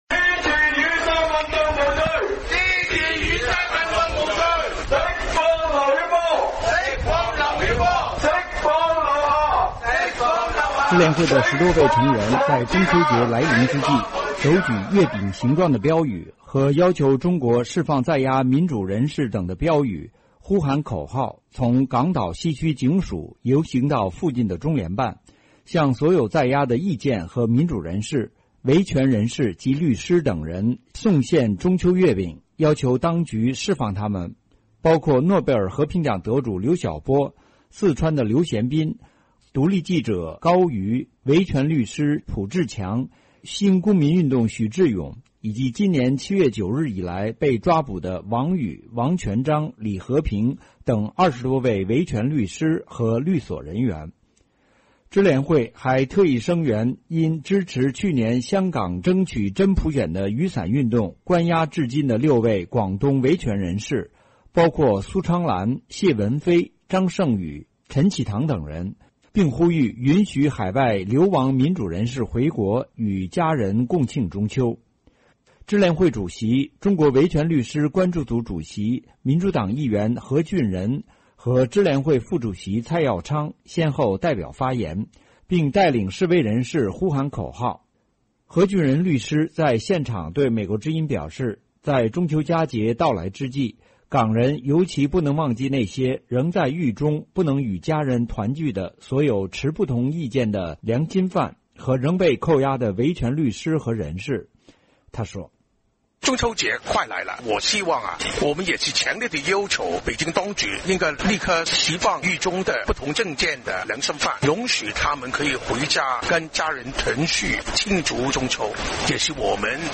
何俊仁律师在现场对美国之音表示，在传统中秋佳节到来之际，港人尤其不能忘记那些仍在狱中不能与家人团聚的所有持不同意见的良心犯和仍被扣押的维权律师和人士。